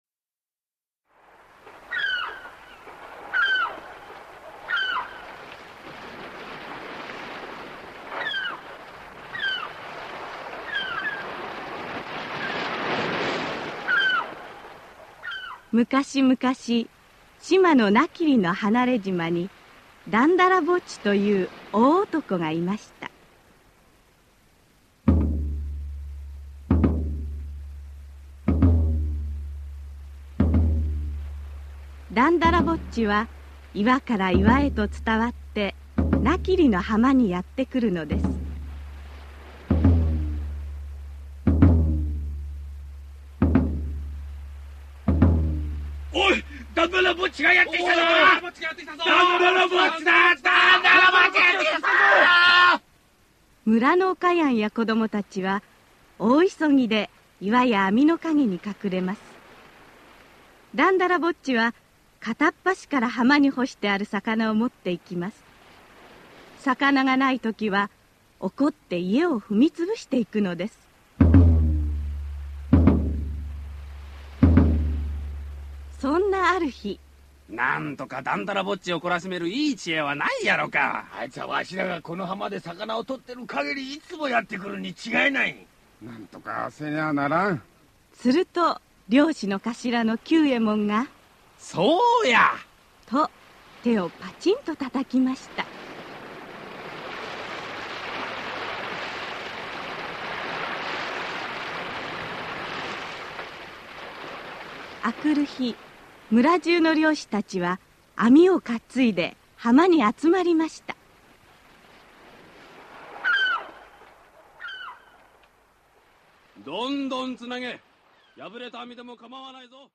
[オーディオブック] だんだらぼっち